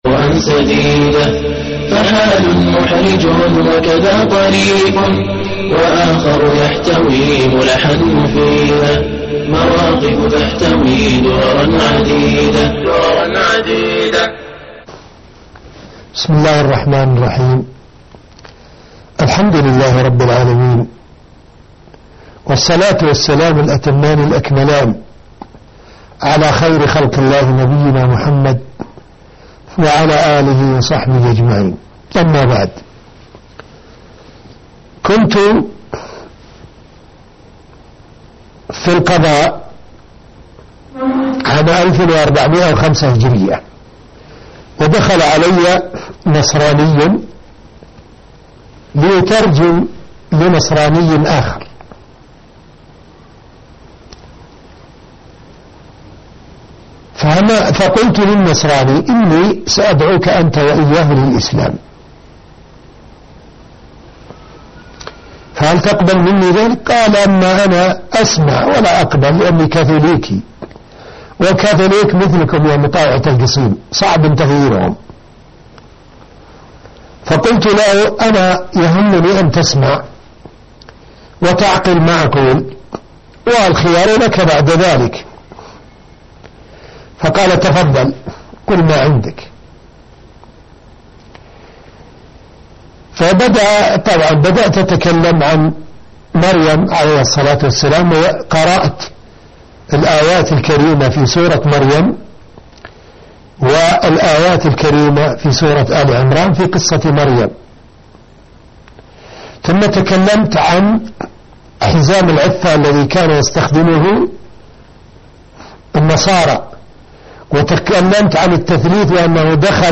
حوار مع نصرانى (14/6/2010)مواقف لفضيلة الشيخ ابراهيم الخضيرى - قسم المنوعات